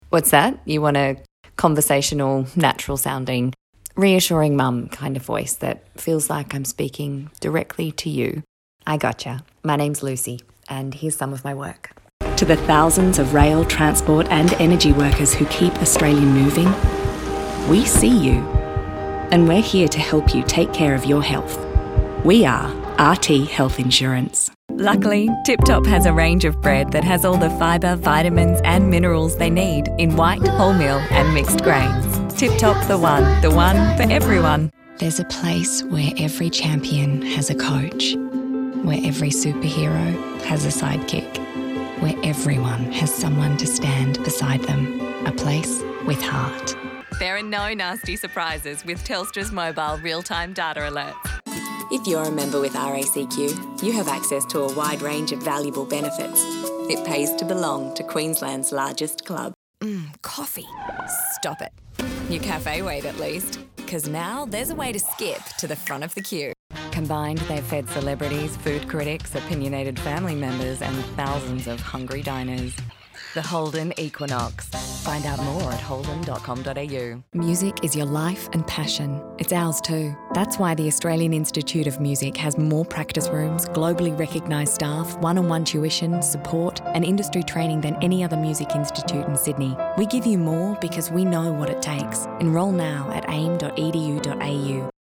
FEMALE VOICE OVER TALENT